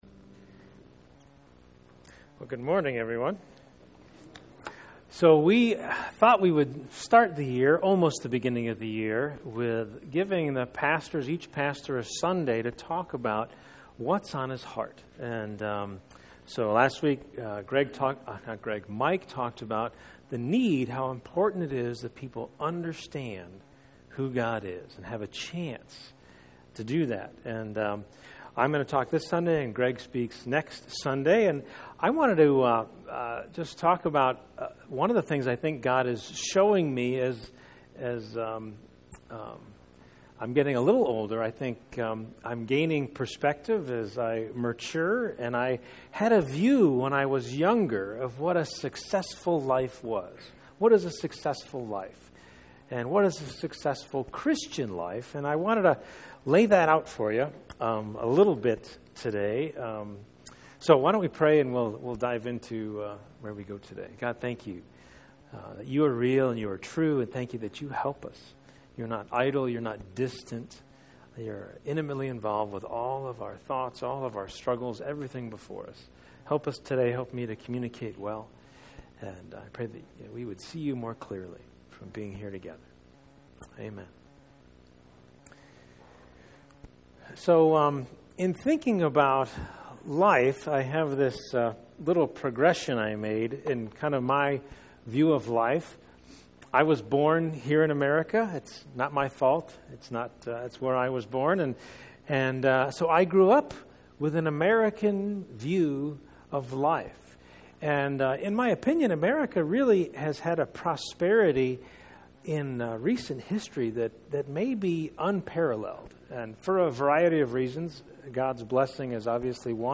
Pastor's Heart Series Service Type: Sunday Morning %todo_render% « Pastor’s Heart for the Church